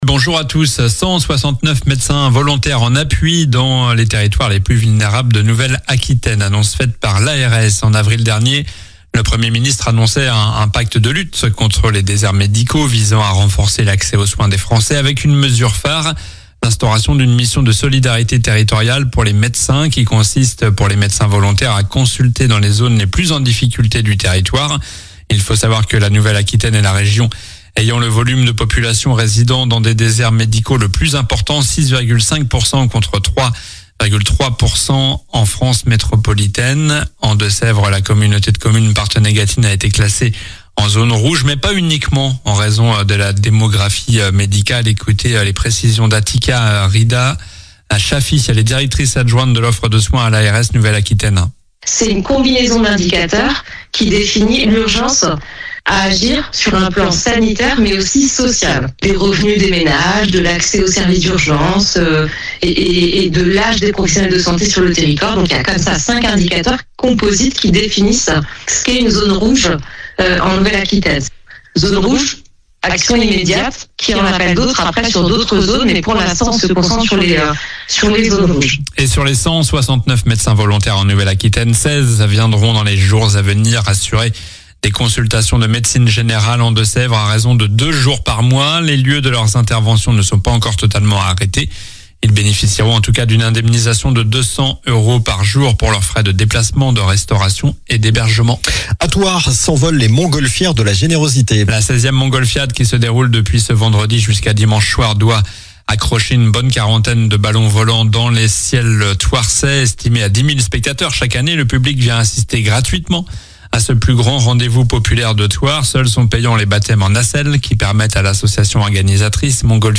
Journal du samedi 06 septembre (matin)